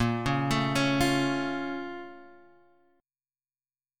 A#mM7 chord